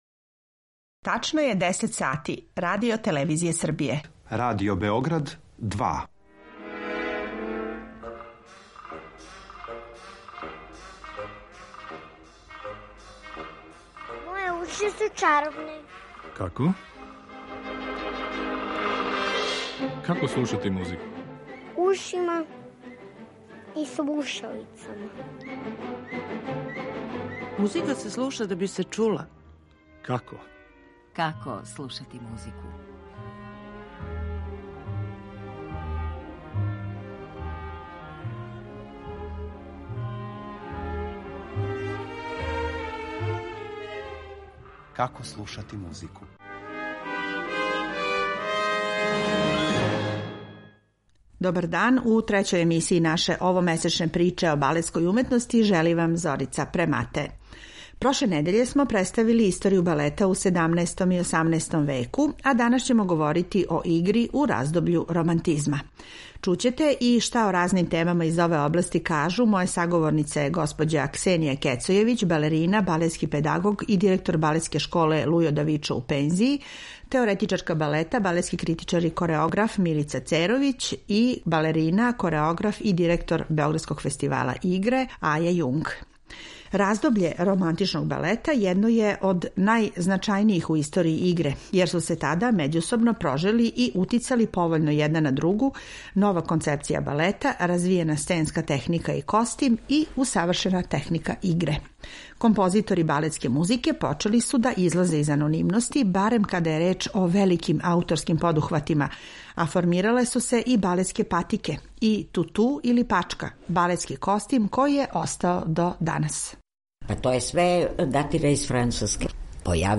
У емисији ћете имати прилику и да чујете одломке мање познатих, као и чувених балета који су обележили историју овог жанра у музици.